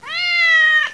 cat.wav